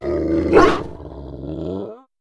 Cri de Dogrino dans Pokémon Écarlate et Violet.